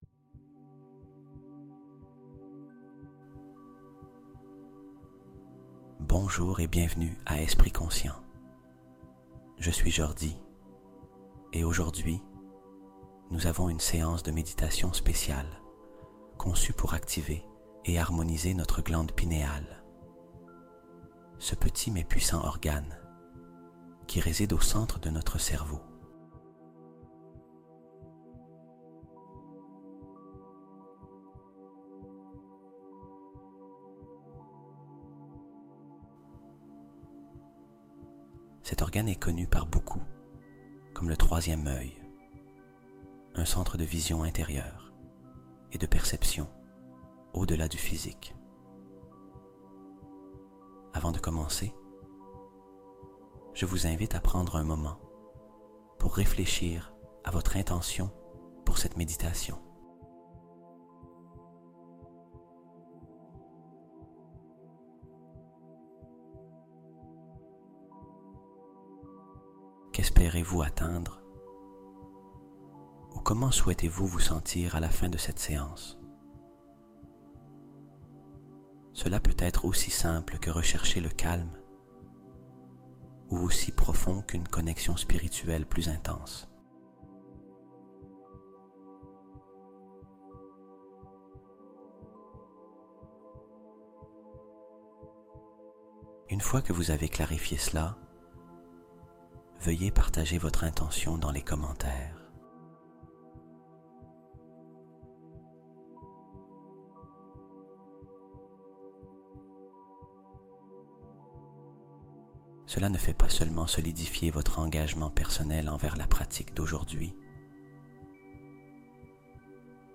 TA GLANDE PINÉALE S'ACTIVE EN 180 SECONDES CHRONO | Ouvre Ton Troisième Œil Avec 741 Hz (Prouvé)